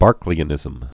(bärklē-ə-nĭzəm, bûr-)